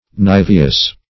niveous - definition of niveous - synonyms, pronunciation, spelling from Free Dictionary
Search Result for " niveous" : The Collaborative International Dictionary of English v.0.48: Niveous \Niv"e*ous\, a. [L. niveus, fr. nix, nivis, snow.]